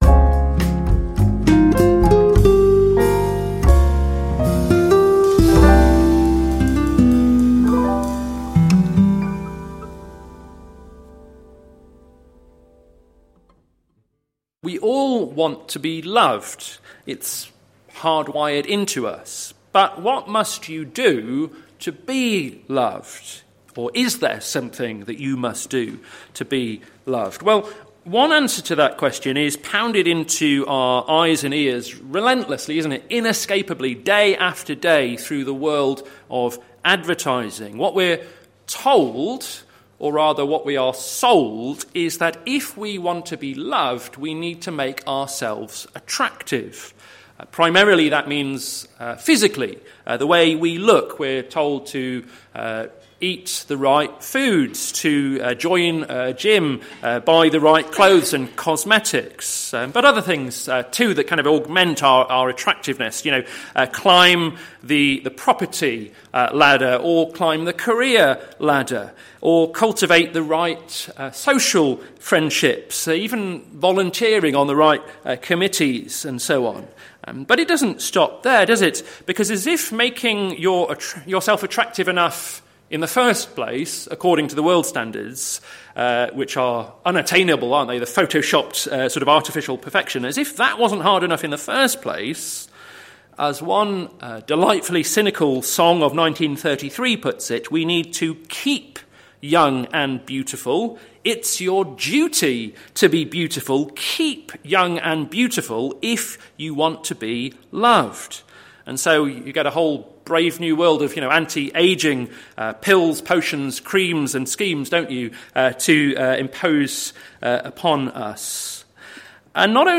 Special services